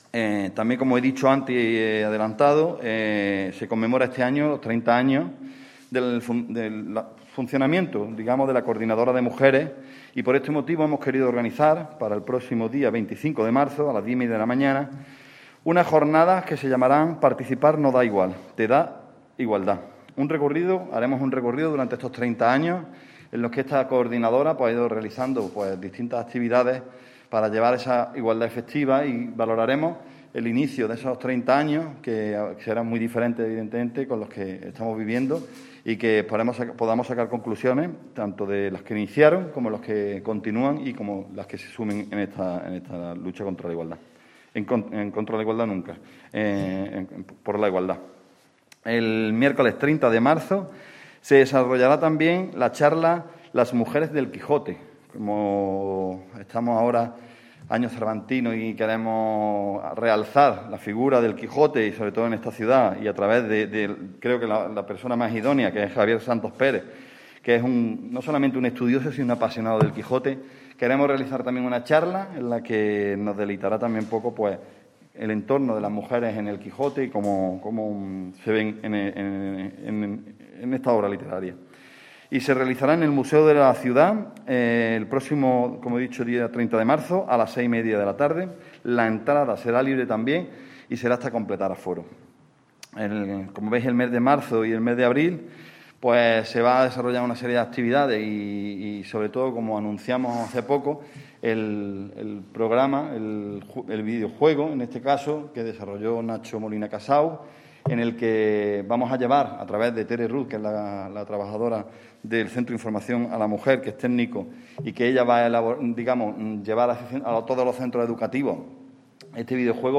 El teniente de alcalde delegado de Programas Sociales e Igualdad, Alberto Arana, ha presentado hoy viernes en rueda de prensa el programa de actividades conmemorativas en nuestra ciudad que se llevarán a cabo en torno a la próxima conmemoración del Día Internacional de la Mujer el martes 8 de marzo.
Cortes de voz